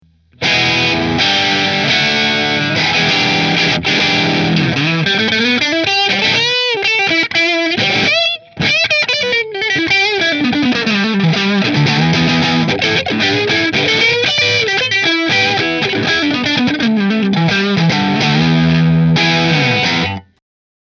Greco Les Paul Custom White Replica Middle Through Marshall